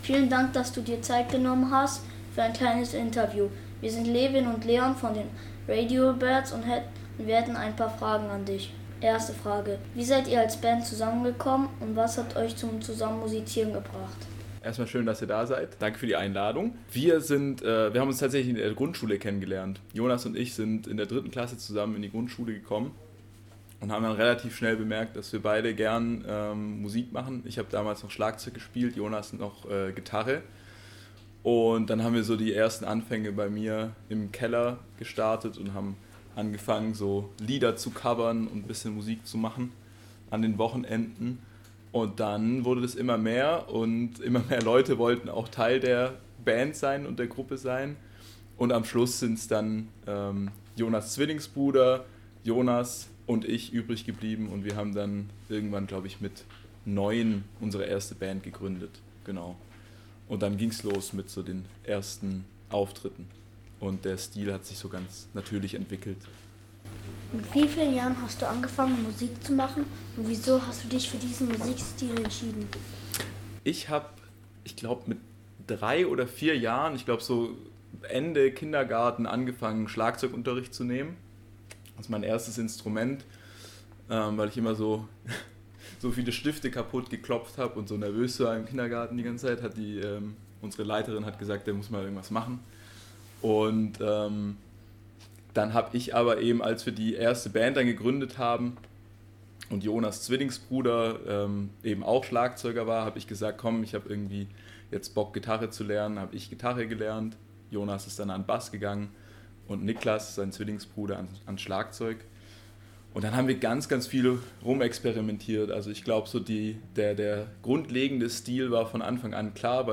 Dieser Eintrag wurde veröffentlicht unter Interview Podcast-Archiv der PH-Freiburg und verschlagwortet mit birds Interview Konstanz radio rock vanholzen Radio Birds Konstanz am von